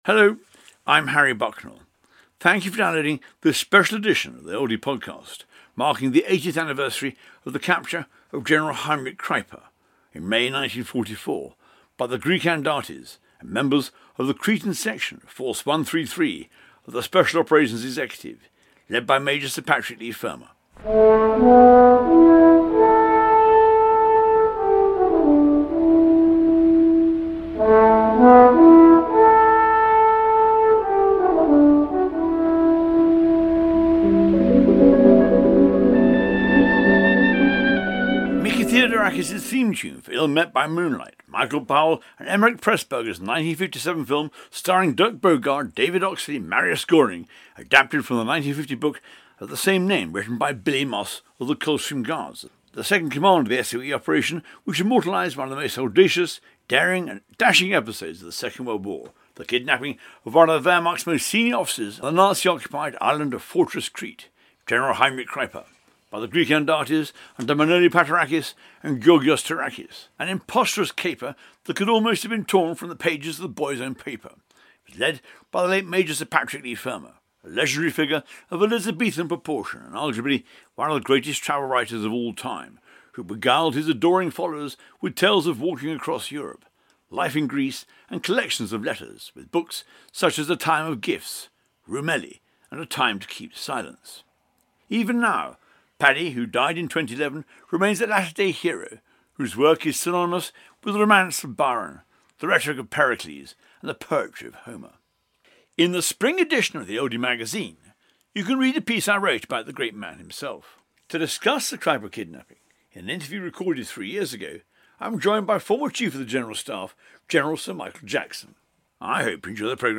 interviews General Sir Michael David Jackson.